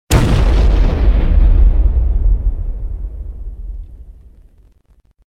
youhit1.ogg